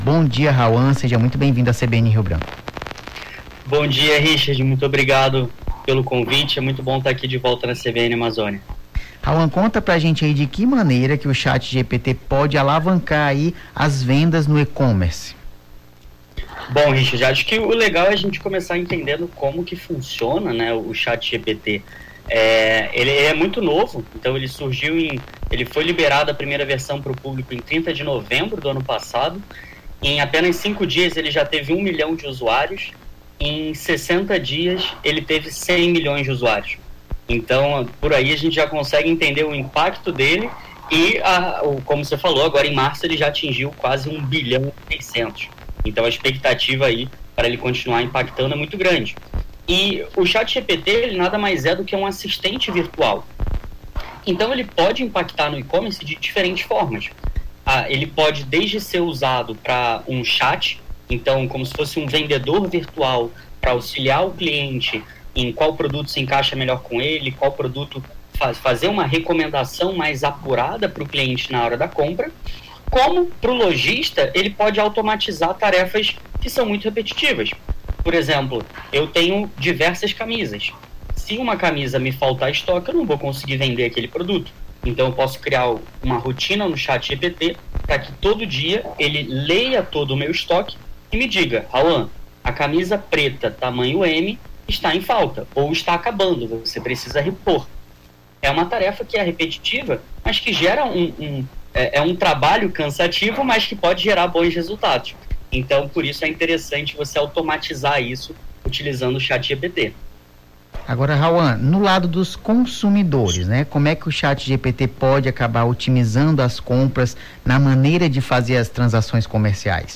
Nome do Artista - CENSURA - ENTREVISTA (CHATGPT NO E-COMMERCE) 18-04-23.mp3